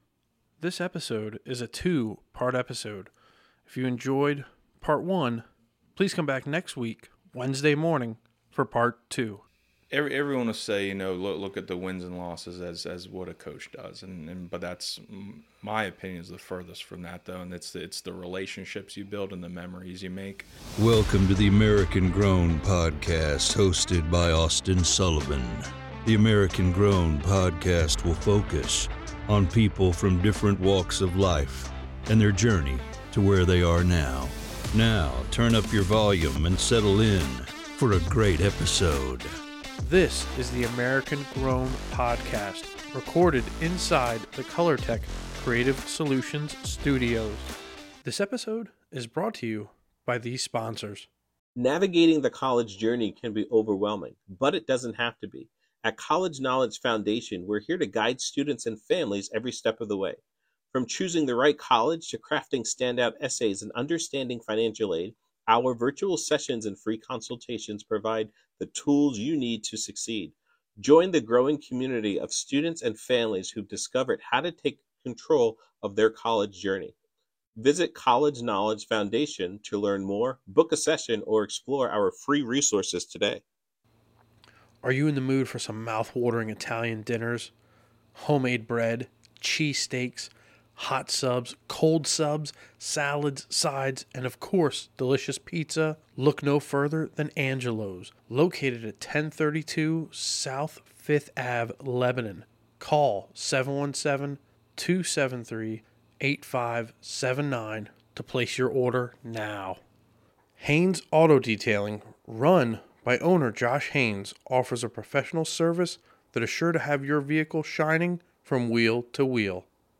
Episode 126: of the American Grown Podcast in the Colortech Creative Solutions studios